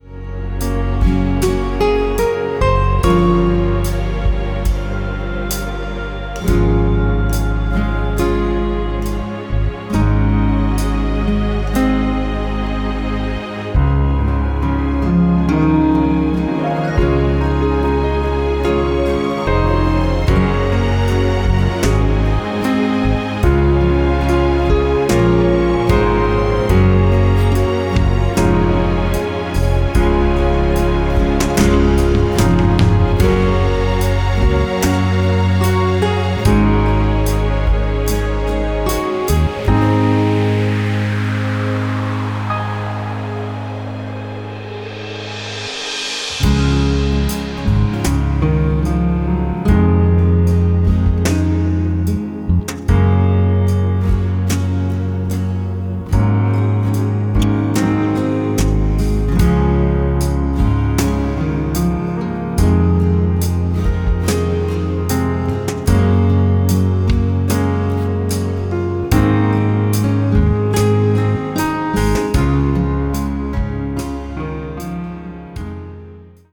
Sax instrumental version.